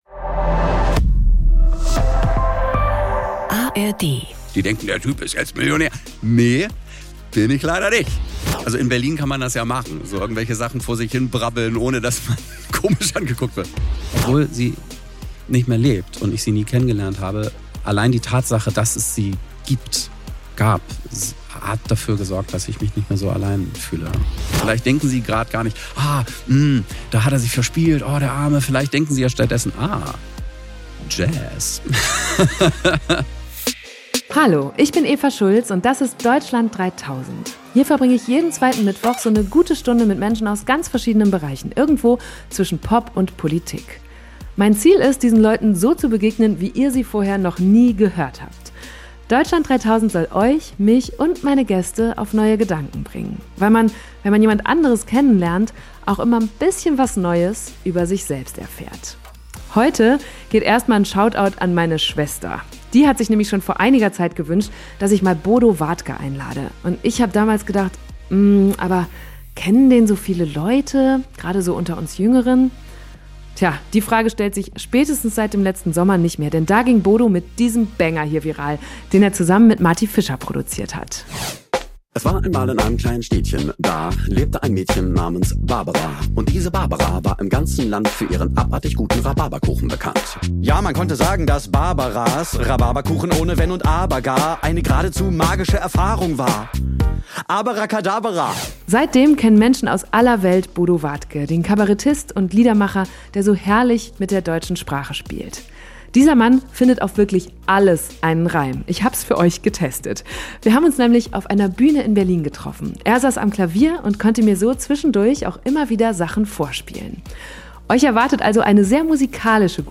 Wir haben uns nämlich auf einer Bühne in Berlin getroffen: Er saß am Klavier und konnte mir so zwischendurch auch immer wieder Sachen vorspielen. Euch erwartet also eine sehr musikalische gute Stunde, in der ich unter anderem gelernt habe, welche Tricks es gibt, um Zungenbrecher zu lernen, wie viel Geld man mit so einem Mega-Viral-Hit auf TikTok verdient und wie man aus Fehlern noch was Gutes ziehen kann.